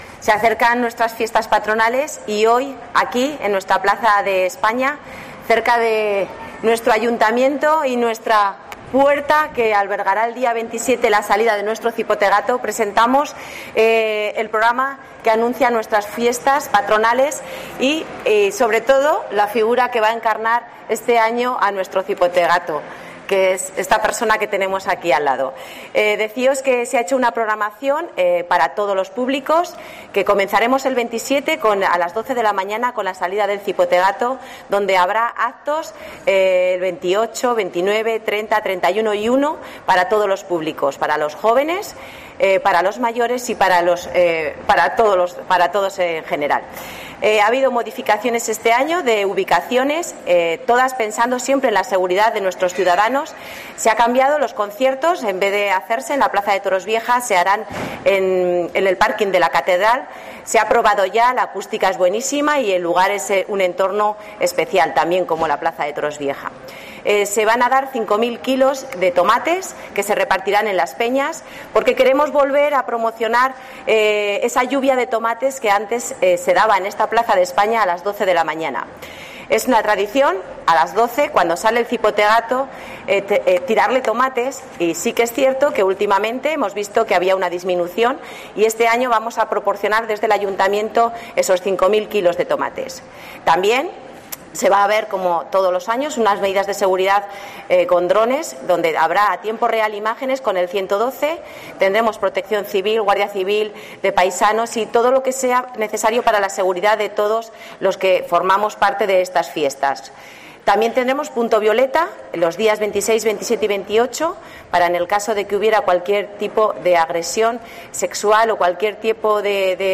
La concejala de festejos de Tarazona, Eva Calvo, presenta las fiestas de este año y al Cipotegato 2022.